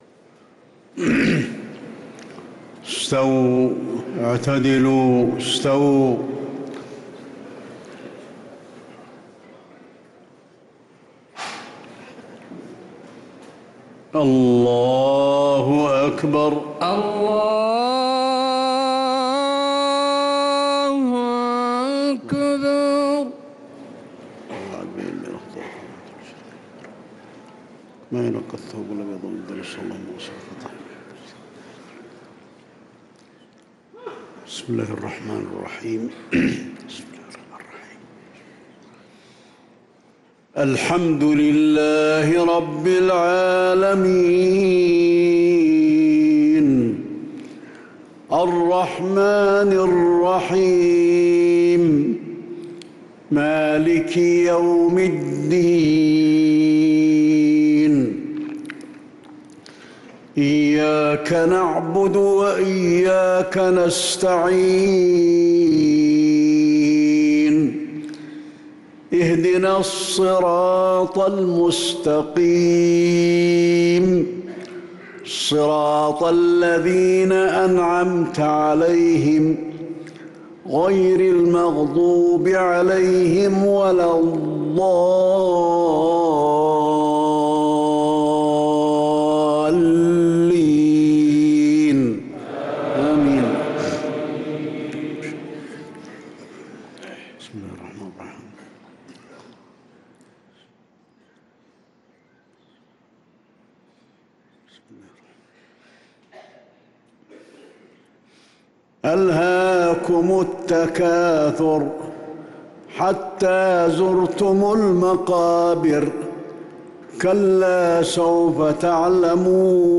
صلاة المغرب للقارئ علي الحذيفي 23 رمضان 1445 هـ
تِلَاوَات الْحَرَمَيْن .